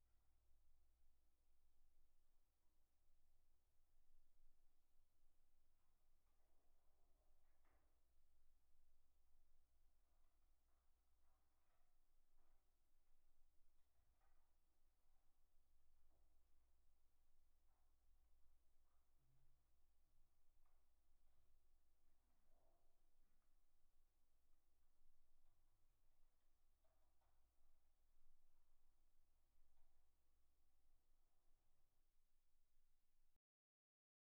This test uses audio samples taken from three everyday scenarios: a busy street, an office, and an airplane cabin.
office-noise.wav